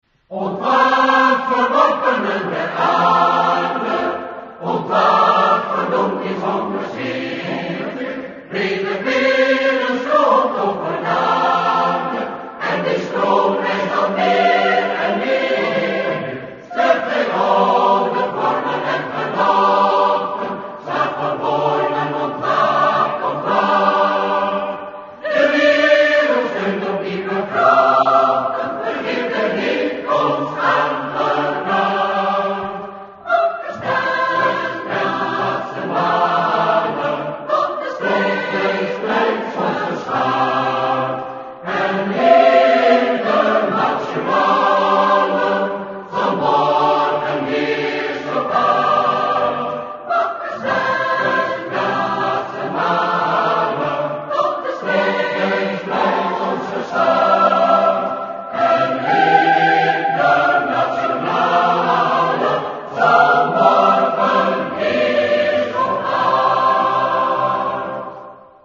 Mp3 digitaal audio bestand (hogere bitrate, stereo)